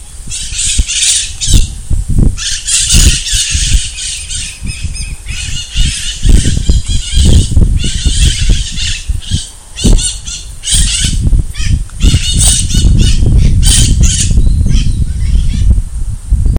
Calancate Ala Roja (Psittacara leucophthalmus)
Nombre en inglés: White-eyed Parakeet
Condición: Silvestre
Certeza: Vocalización Grabada